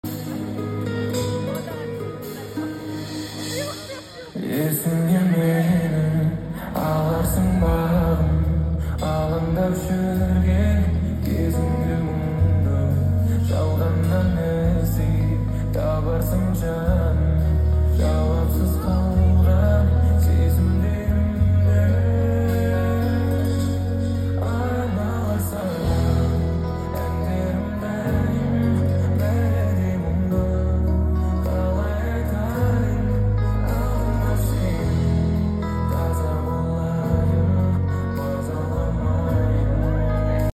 жанды дауыс 🔥🔥
очень хороший тембр у него
Концерт четко болды